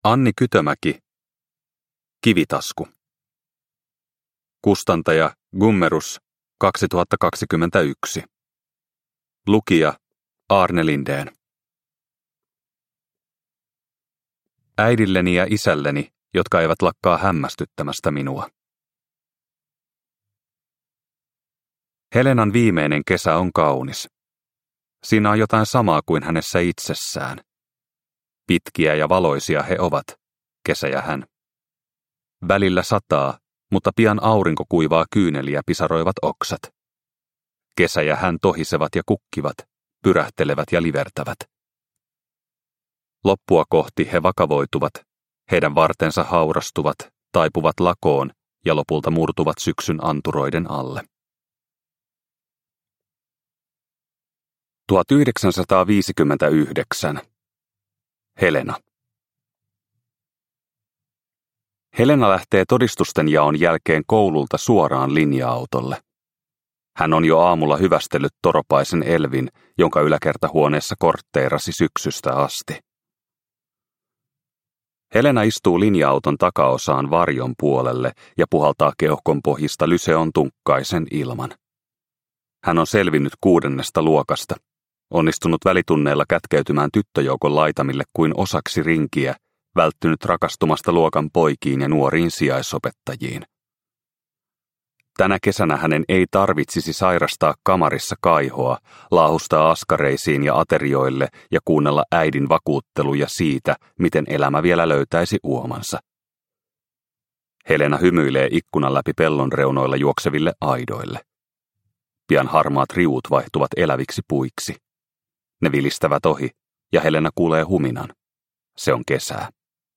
Kivitasku – Ljudbok – Laddas ner